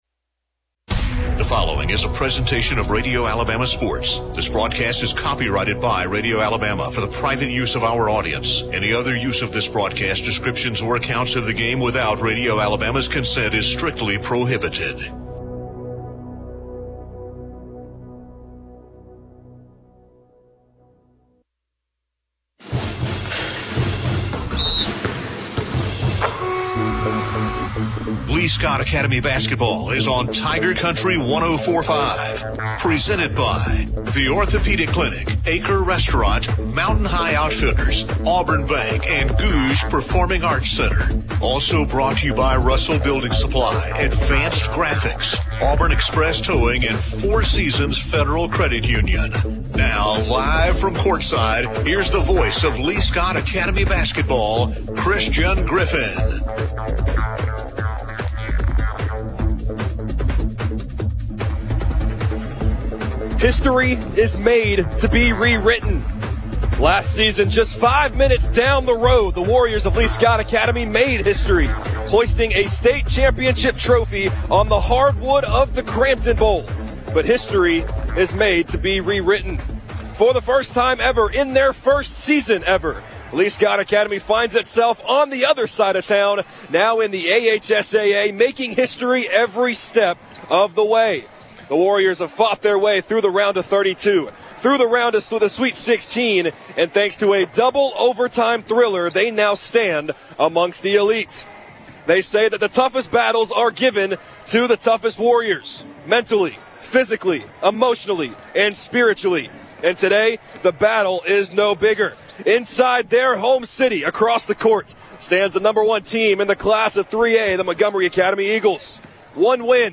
calls Lee-Scott Academy's game versus Montgomery Academy in the State Tournament Elite Eight. The Warriors lost 68-35.